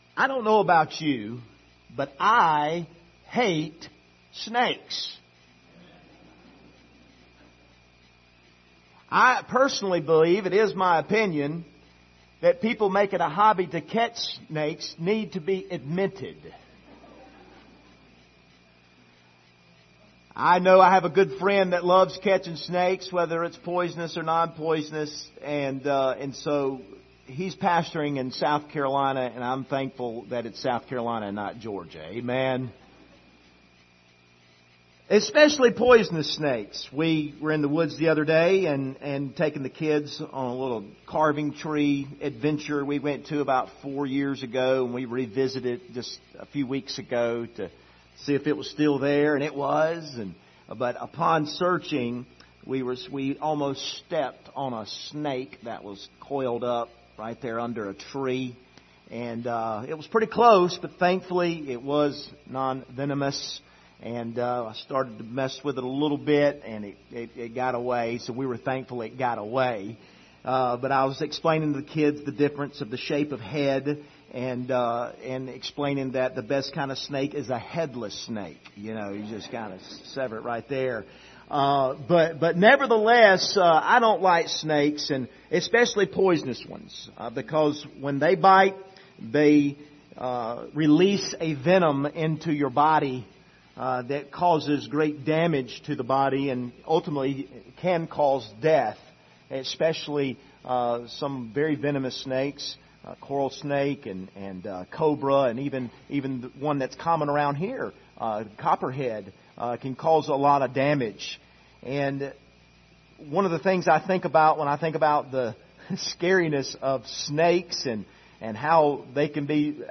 Esther 8 Service Type: Sunday Morning « Mary Magdalene